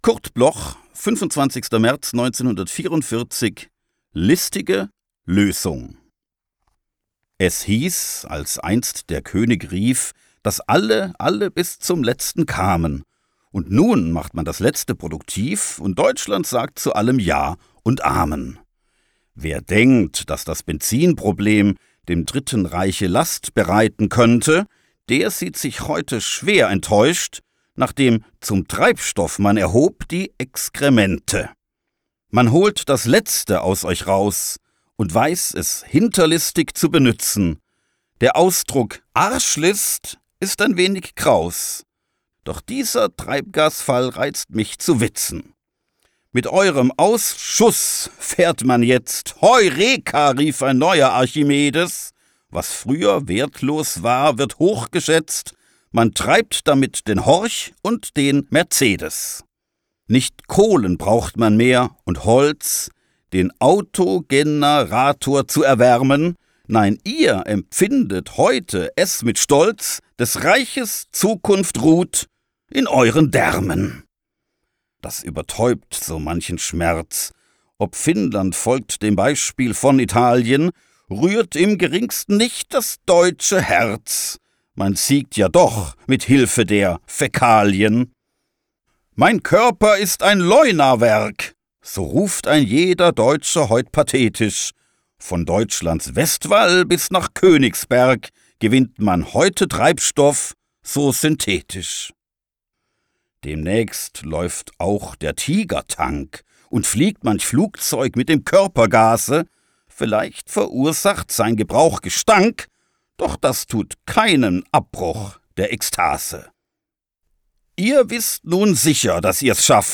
Aufnahme: Tonstudio Kristen & Schmidt, Wiesbaden